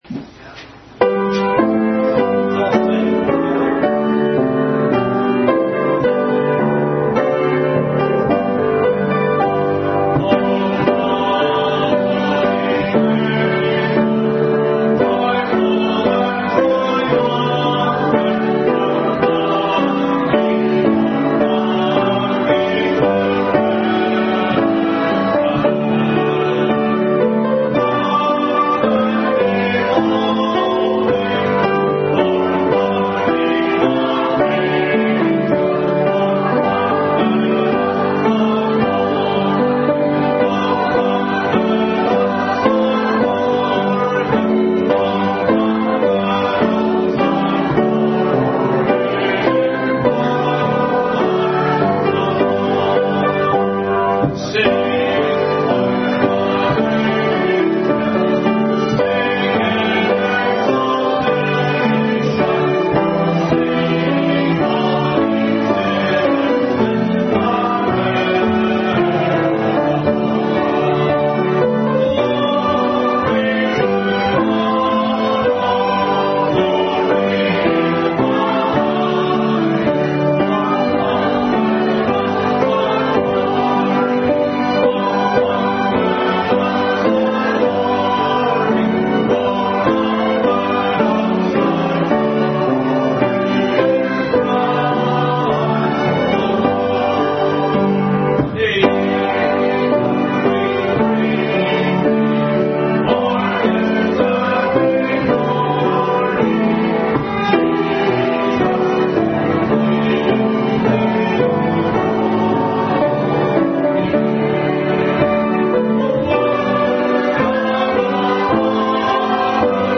2021 Christmas Program
Service Type: Family Bible Hour